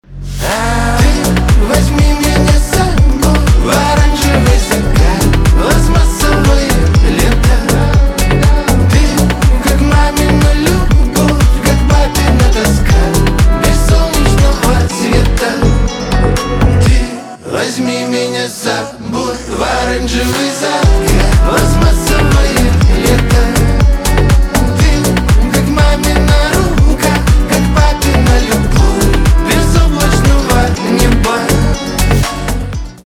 поп
битовые , гитара